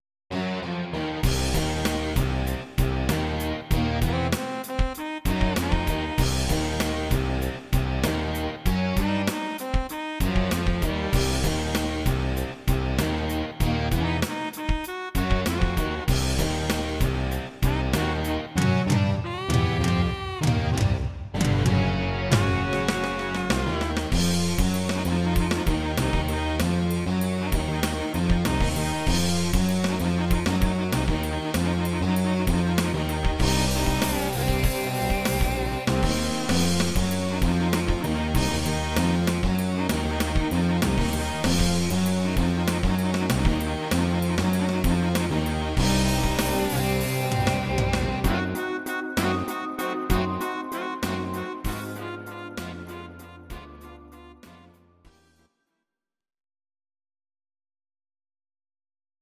Audio Recordings based on Midi-files
Rock, 1980s